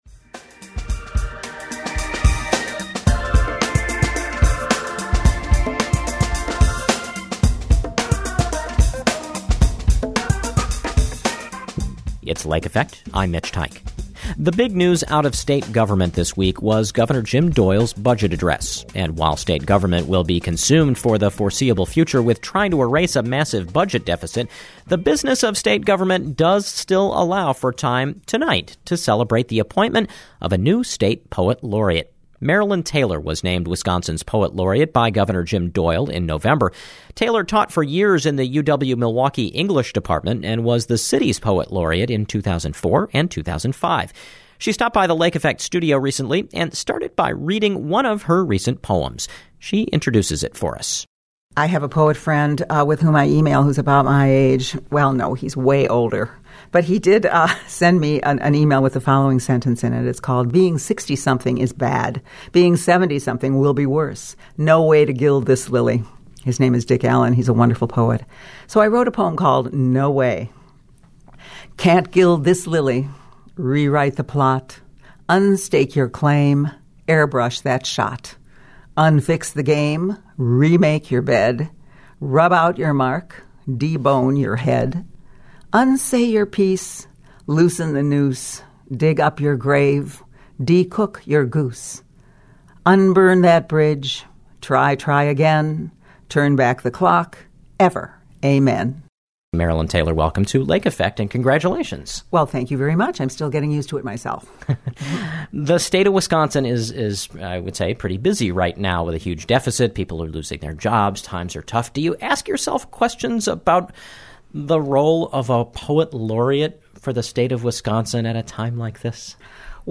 15-minute interview